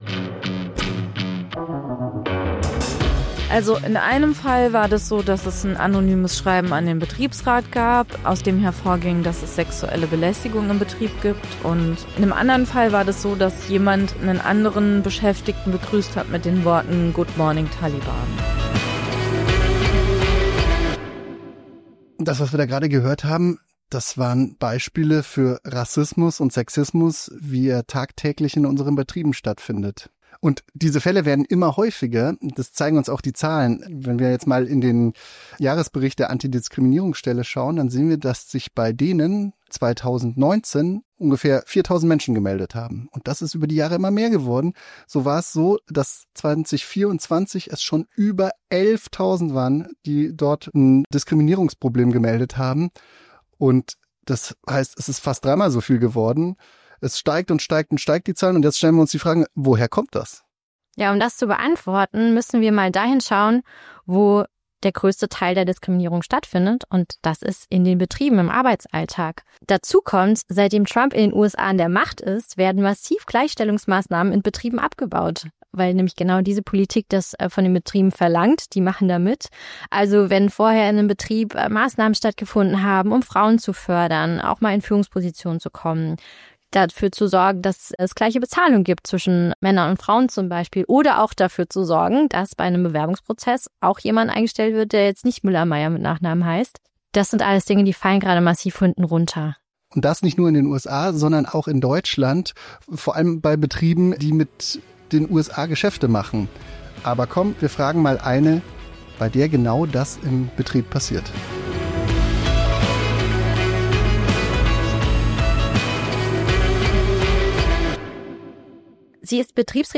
mit einer Betriebsrätin, die sich gegen das Rückfahren der Gleichstellungsmaßnahmen bei ihr im Betrieb wehrt